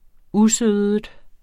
Udtale [ ˈuˌsøˀðəd ] eller [ ˈuˌsøːðəd ]